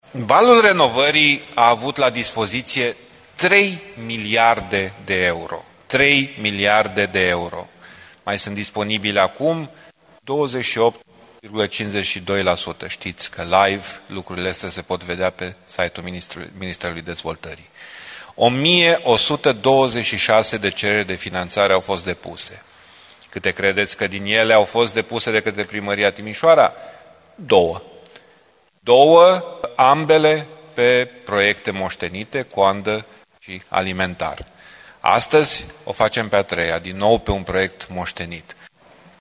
Consilierul liberal Dan Diaconu a reclamat lipsa de proiecte întocmite de Primăria Timișoara pentru a atrage banii europeni disponibili prin PNRR.